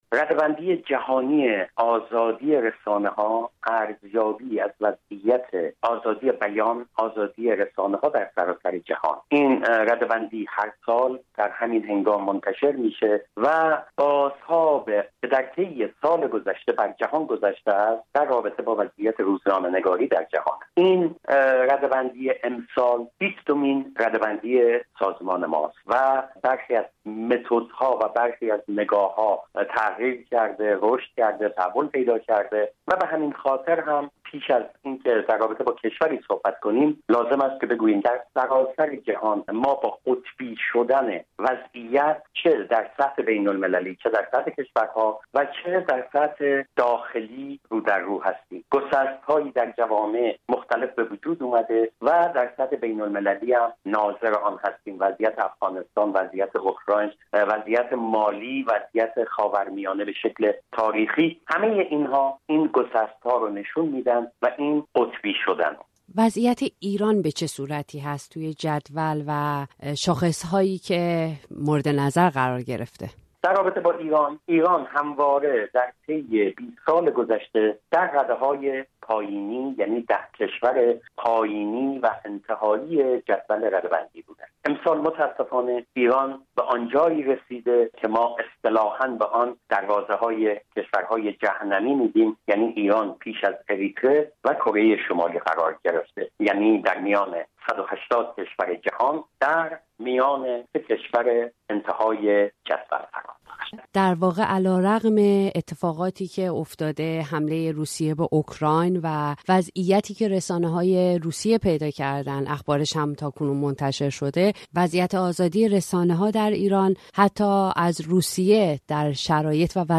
از رادیو فردا